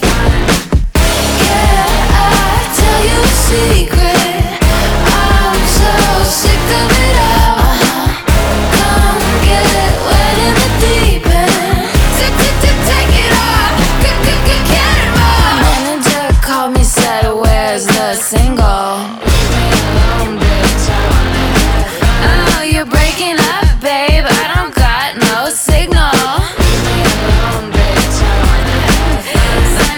Жанр: Поп музыка